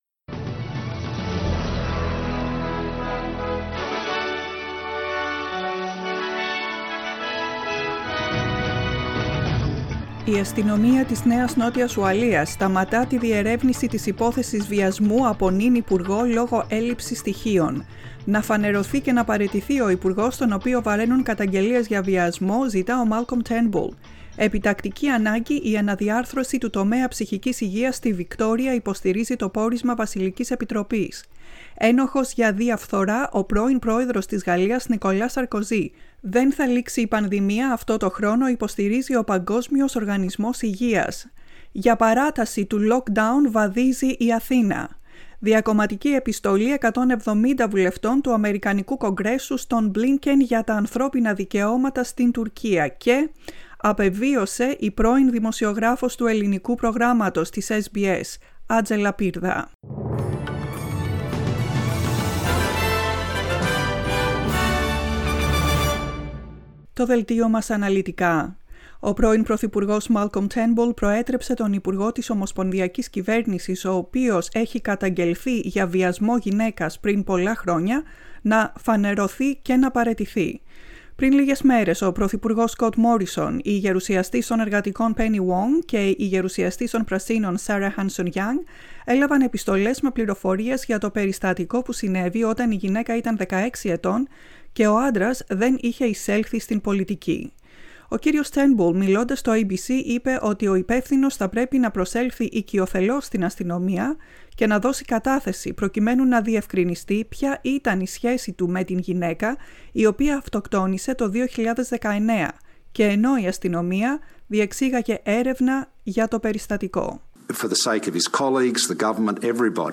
Δελτίο Ειδήσεων στα Ελληνικά, 2.3.2021
Το αναλυτικό δελτίο ειδήσεων της ημέρας